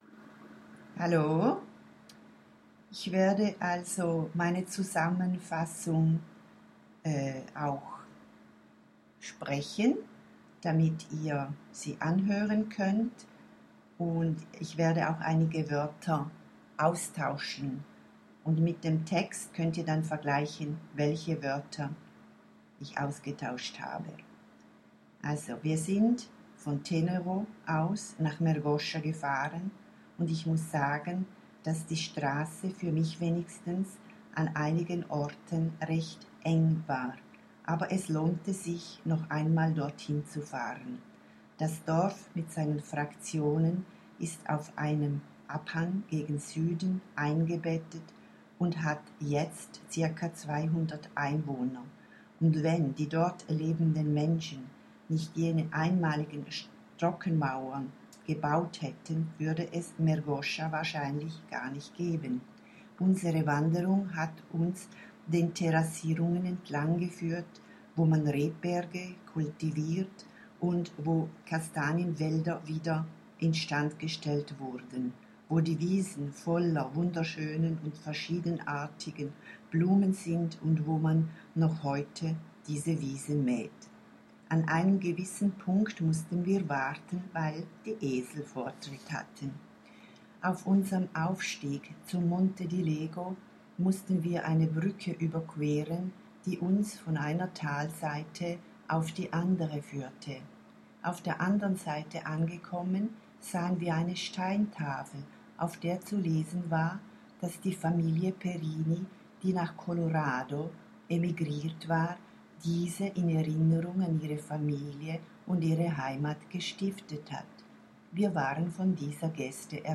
Hören/Hörverständnis : Ich habe 10 Wörter vom geschriebenen Text abgeändert.